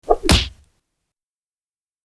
Tiếng Đánh vào cơ thể Bịch…
Thể loại: Đánh nhau, vũ khí
tieng-danh-vao-co-the-bich-www_tiengdong_com.mp3